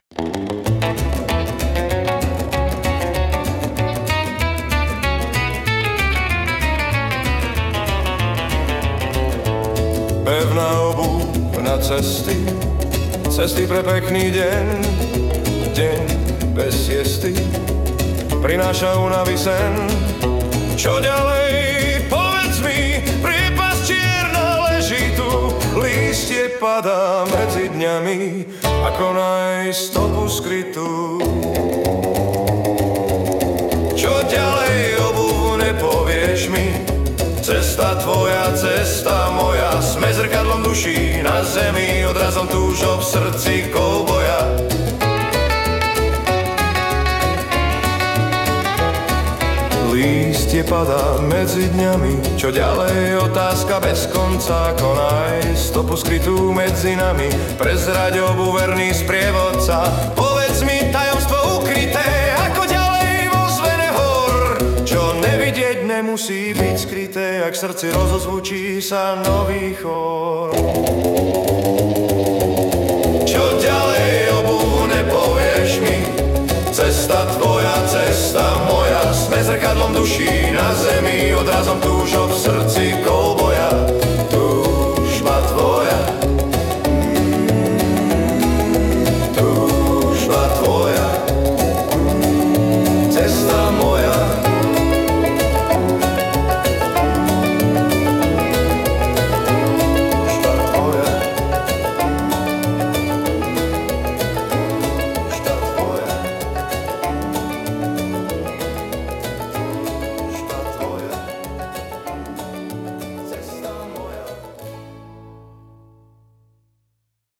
Hudba a spev AI
Balady, romance » Příroda